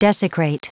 Prononciation du mot desecrate en anglais (fichier audio)
Prononciation du mot : desecrate